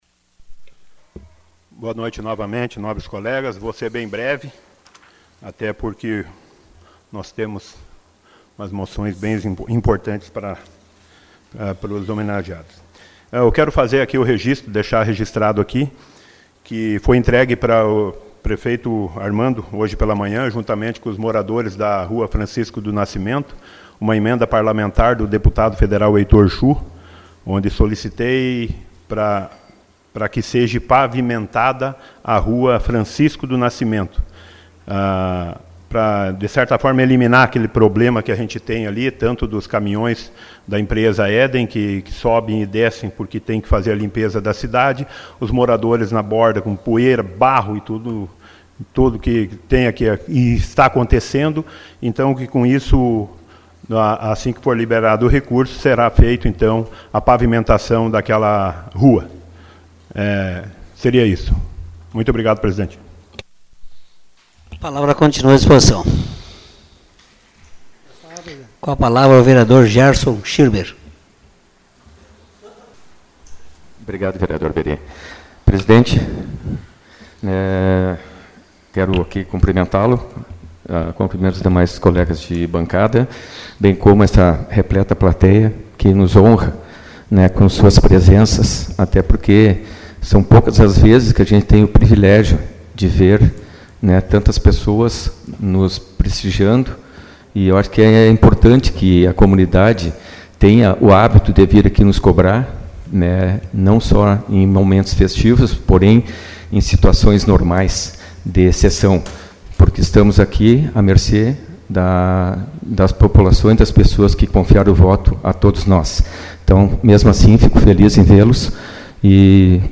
Os vereadores de Sobradinho estiveram reunidos nesta segunda-feira (06), na 4ª Sessão Ordinária de 2023.
Confira os pronunciamentos na tribuna: